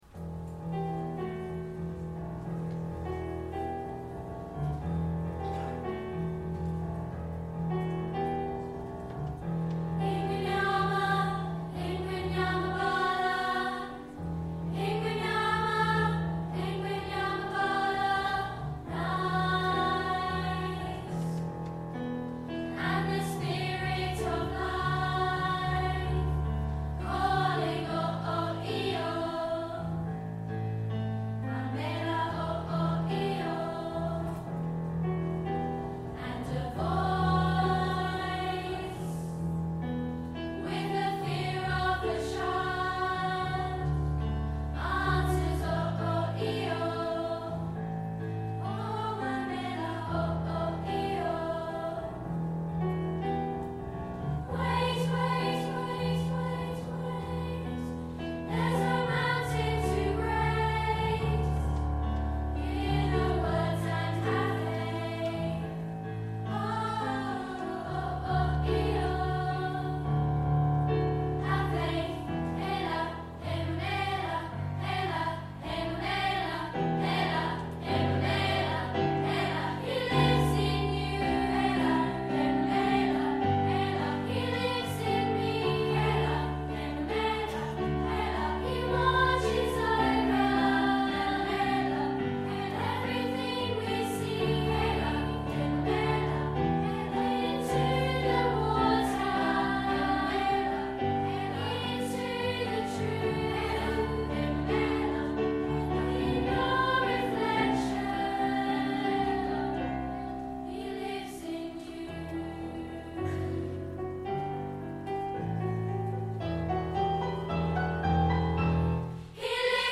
Summer Concert 2014